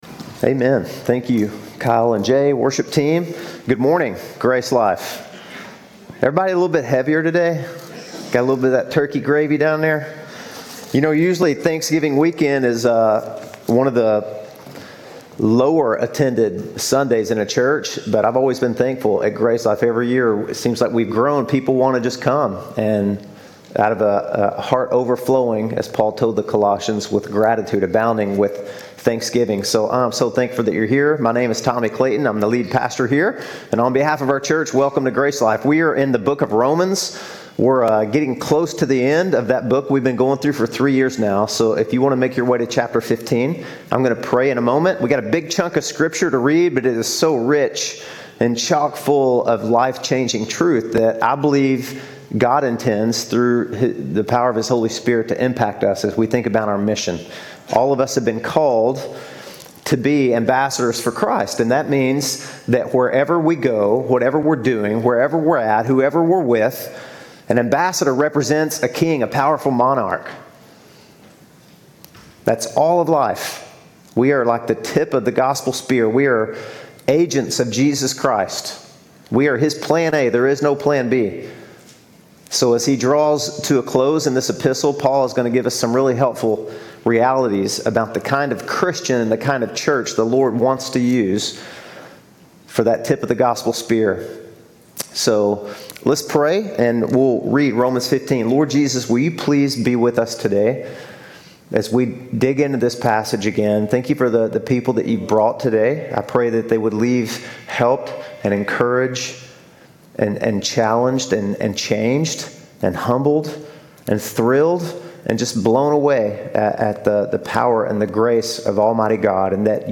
The Mission-Minded Church Part 2 Sermons podcast For at give dig den bedst mulige oplevelse bruger dette websted cookies.